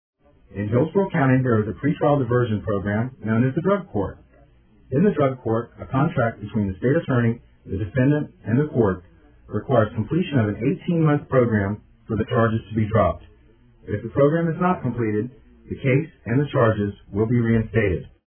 DUI Progression Described By a Board Certified Lawyer Go Over Each Step of a DUI in Tampa Courts